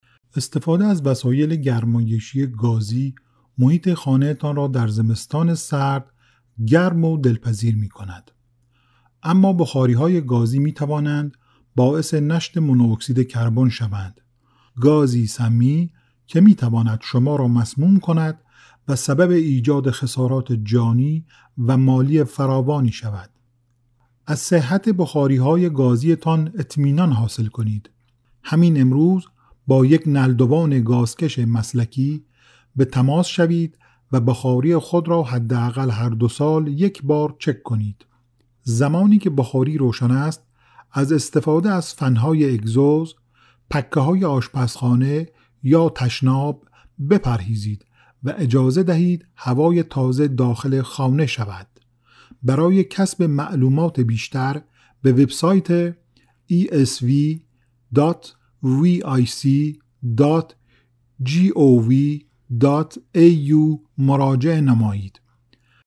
Male
Adult
Educational